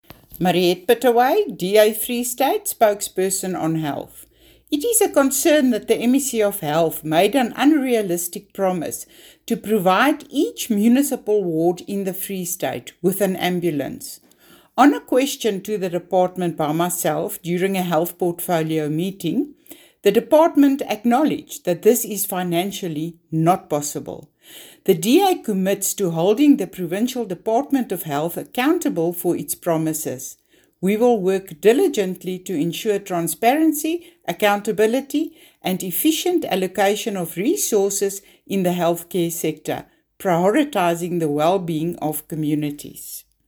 Issued by Mariette Pittaway MPL – DA Free State Spokesperson: Health & Education
Afrikaans soundbites by Mariette Pittaway MPL and